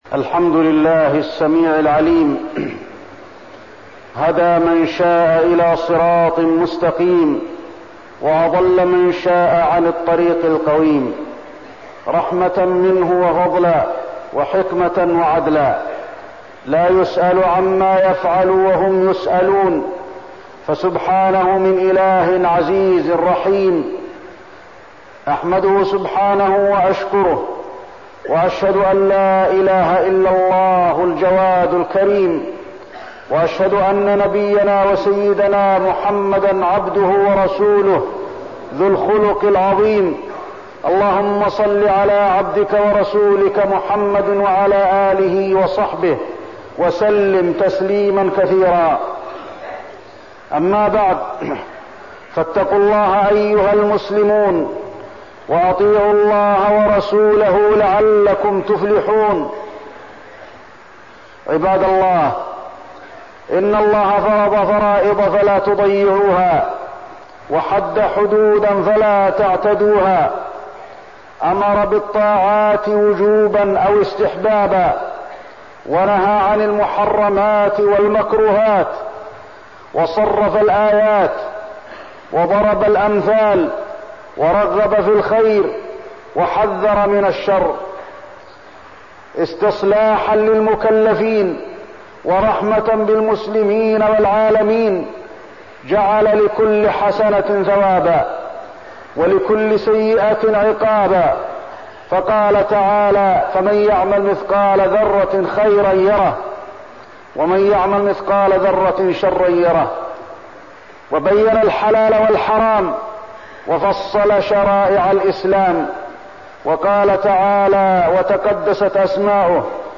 تاريخ النشر ١٨ ذو القعدة ١٤١٤ هـ المكان: المسجد النبوي الشيخ: فضيلة الشيخ د. علي بن عبدالرحمن الحذيفي فضيلة الشيخ د. علي بن عبدالرحمن الحذيفي صلاح القلوب